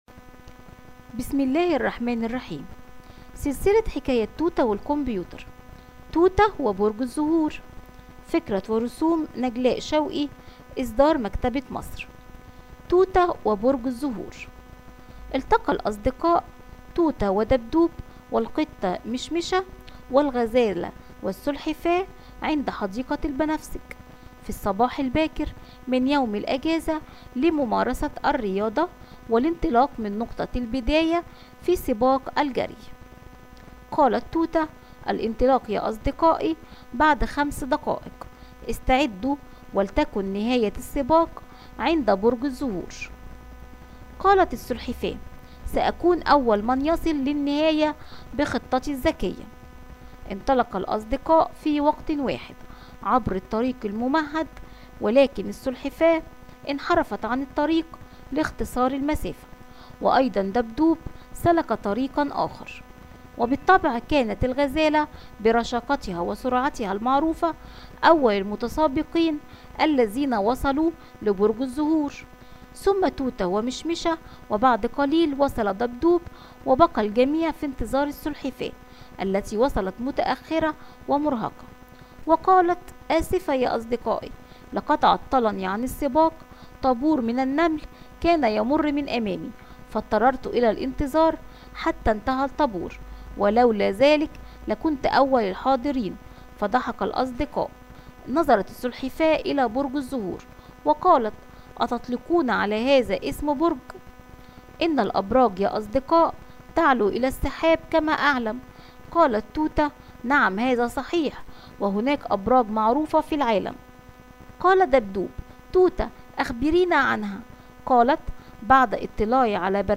Tute ve Çiçek Kulesi – Arapça Sesli Hikayeler
Tute-ve-cicek-kulesi-arapca-sesli-hikayeler.mp3